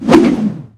tentakle.wav